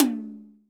• Hi Tom D Key 07.wav
Royality free tom sound tuned to the D note. Loudest frequency: 2652Hz
hi-tom-d-key-07-aJi.wav